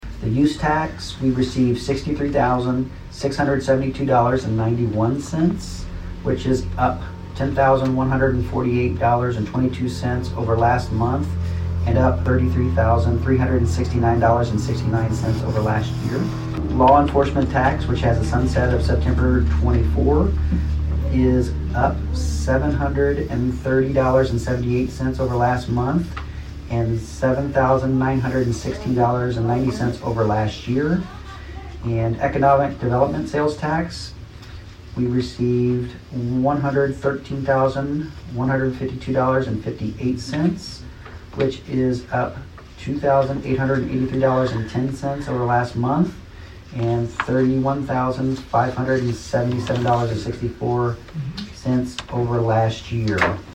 Saline County Treasurer Jared Brewer delivered the sales-tax report for March during the meeting of the county commission on Thursday, March 11.